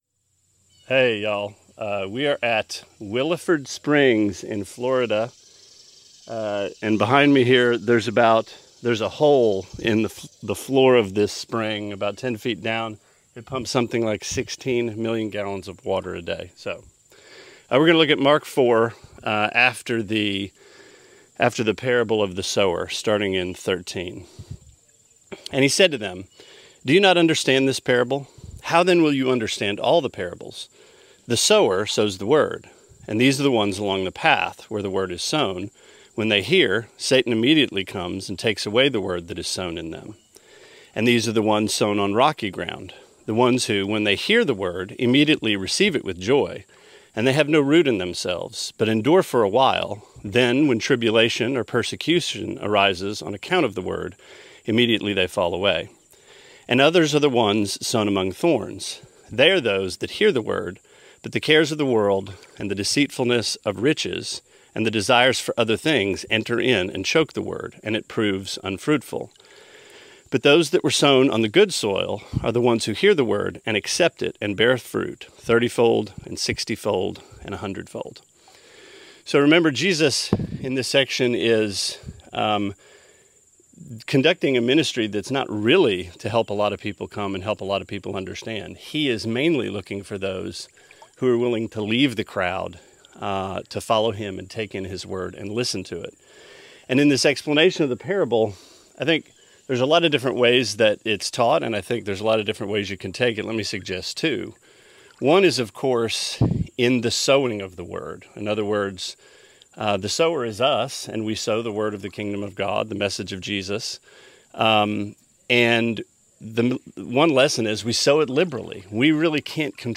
Sermonette 6/27: Mark 4:13-20: Scattered